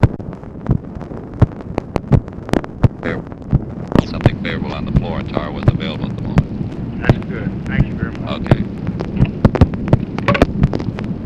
Conversation with LARRY O'BRIEN, July 21, 1965
Secret White House Tapes